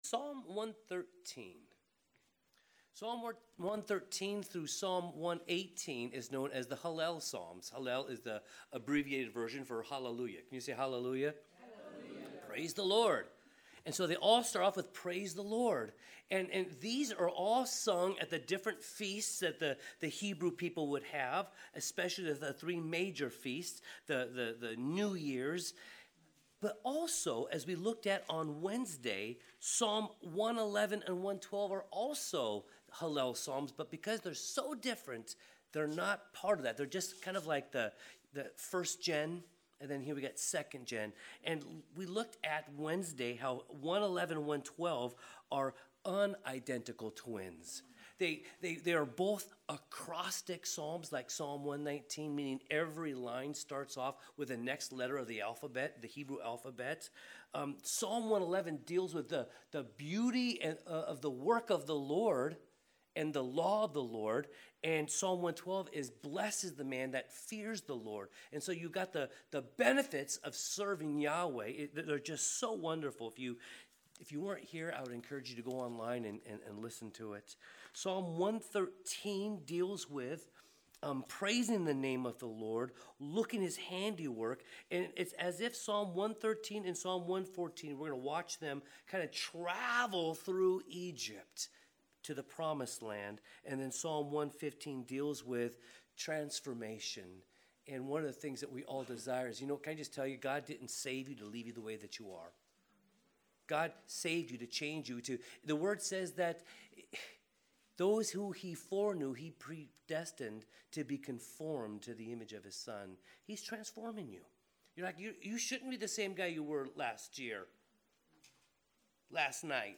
Sermons | Calvary Chapel Lighthouse Fellowship